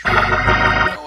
my my organ Meme Sound Effect